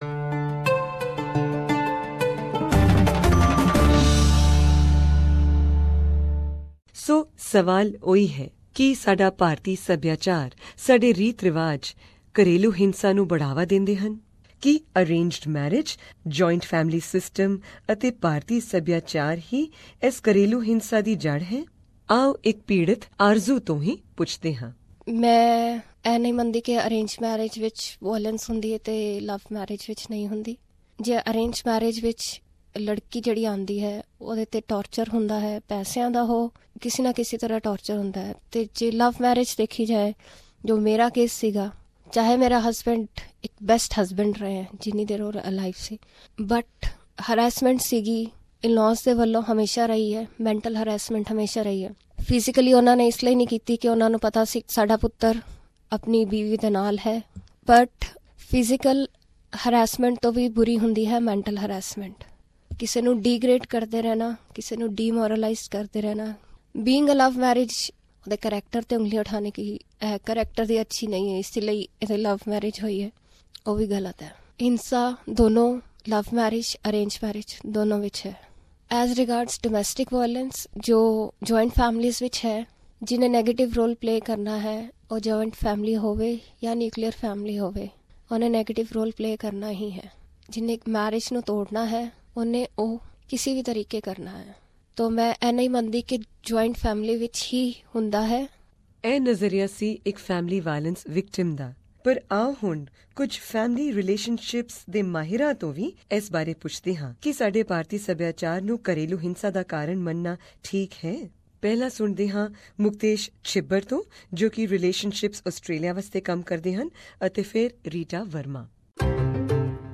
This is the fifth episode of our multi-award winning documentary on family violence in the Indian community of Australia, the Enemy Within.
Many victims and four experts share their opinions with us in this episode.We also ask the question - is the arrival of so many Indian international students to Australia another contributory factor?